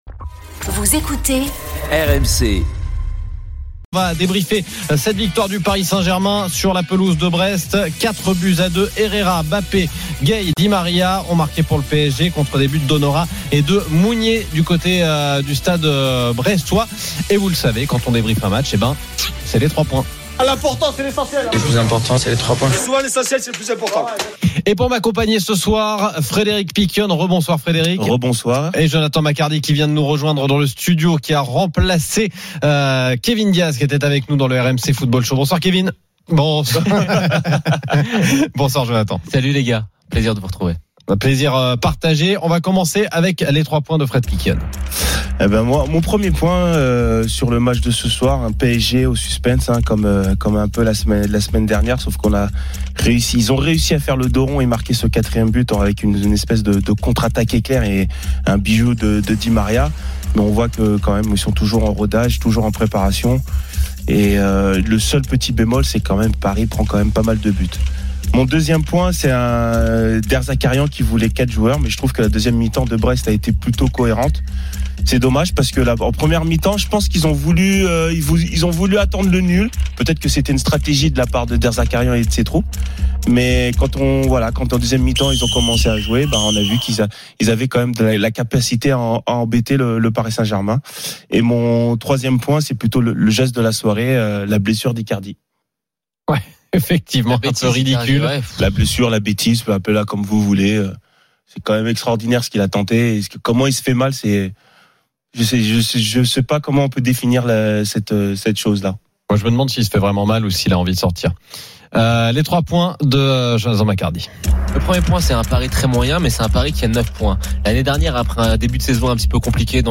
les réactions des joueurs et entraîneurs, les conférences de presse d'après-match et les débats animés entre supporters, experts de l'After et auditeurs.